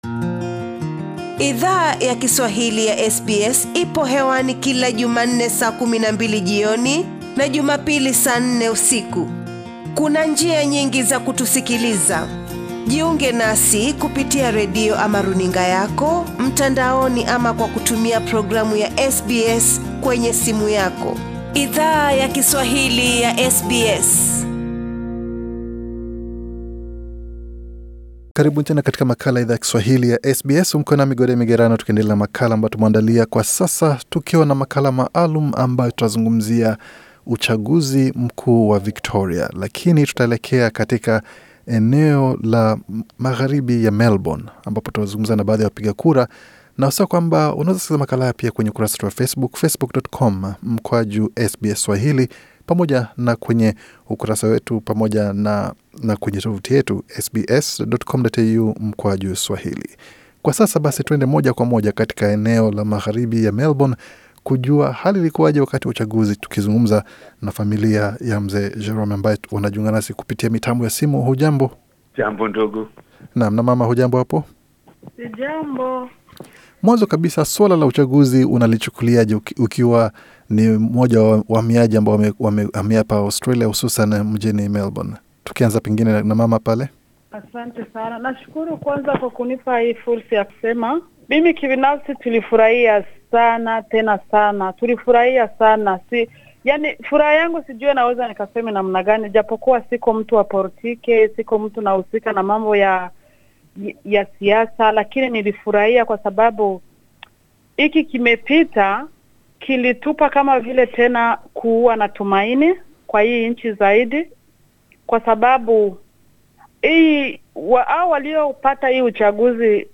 Hesabu ya kura inapo endelea, chama cha Labor cha Victoria kina tarajia kushinda zaidi ya viti 61 katika bunge lenye viti 88. Wapiga kura walio shiriki katika uchaguzi huo, walizungumza na SBS Swahili kuhusu uzoefu wao na matarajio yao toka kwa serikali teuli ya Labor.